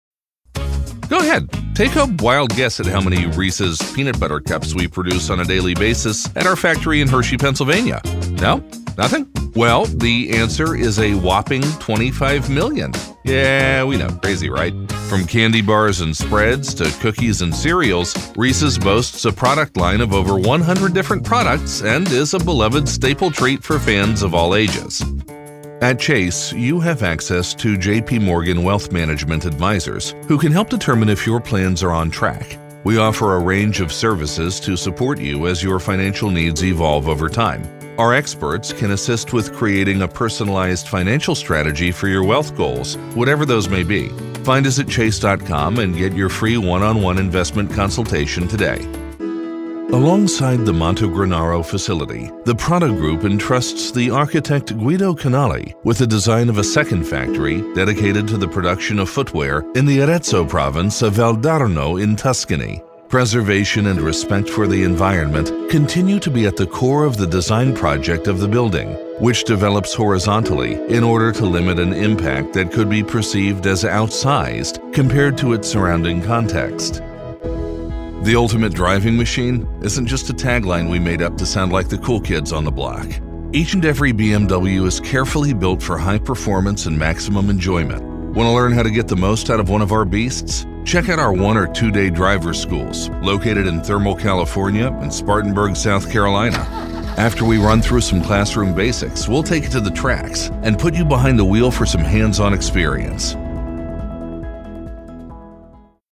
Trustworthy, Lively, Guy next door.
Corporate